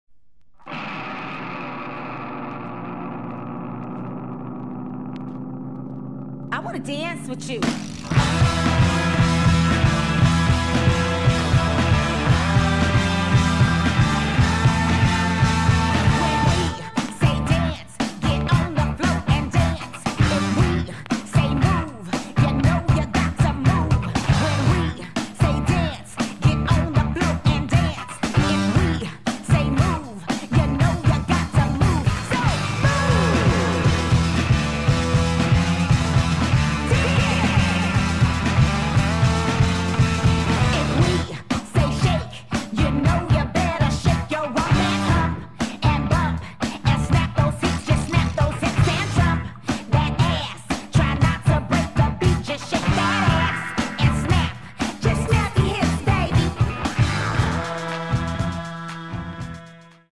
an uptempo rhythm stomper overlaid with heavy guitar fuzz.